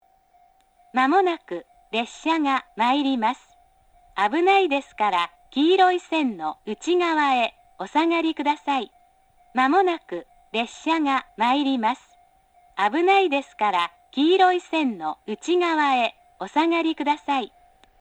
接近放送　女声